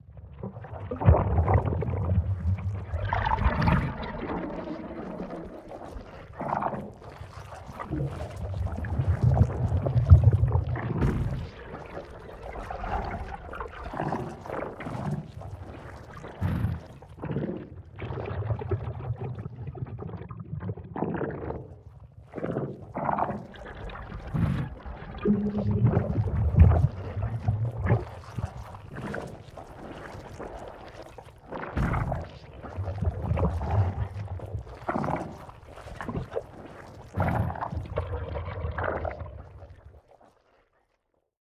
spawner-spitter.ogg